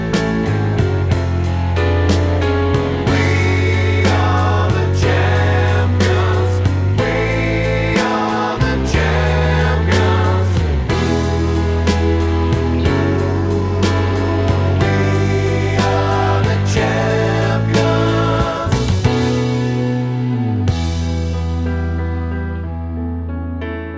no Backing Vocals Rock 3:08 Buy £1.50